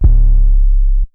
[808] Dro Main.wav